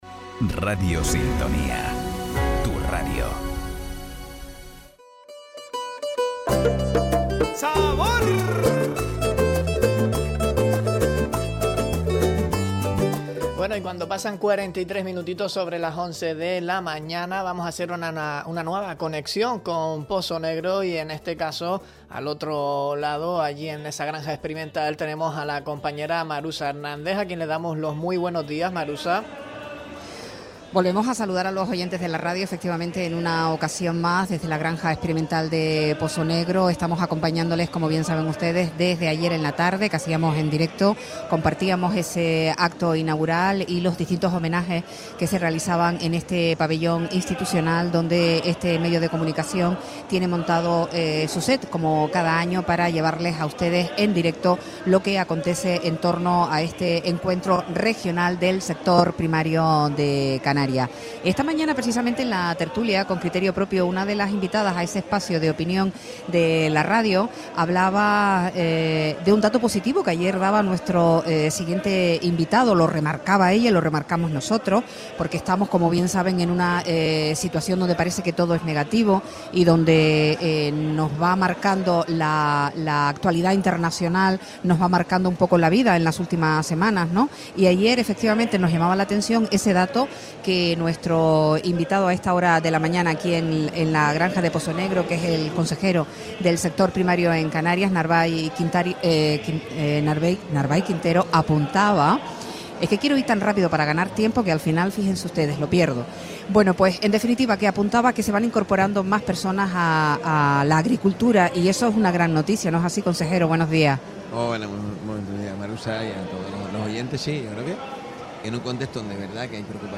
Visita de Narvay Quintero, consejero de Agricultura, Ganadería, Pesca y Soberanía Alimentaria del Gobierno de Canarias, el set de Radio Sintonía en Feaga 2026 - Radio Sintonía
Entrevistas